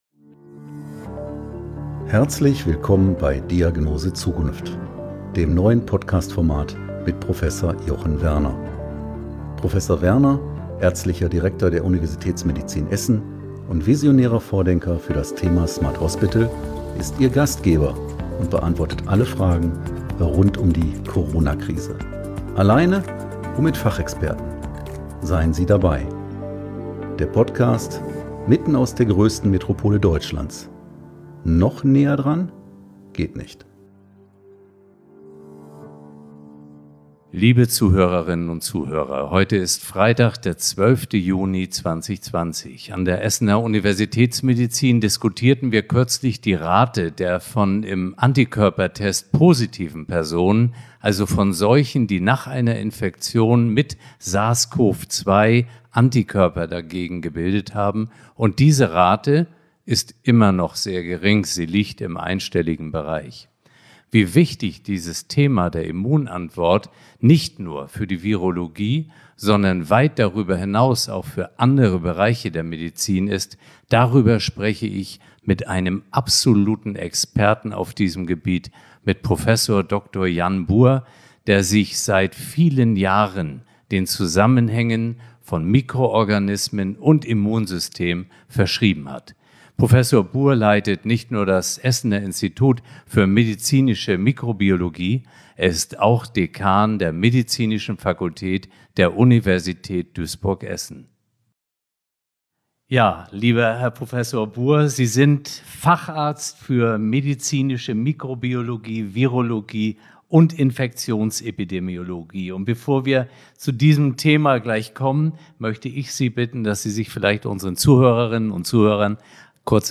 Stets im Austausch mit unterschiedlichen Experten aus den jeweiligen Branchen.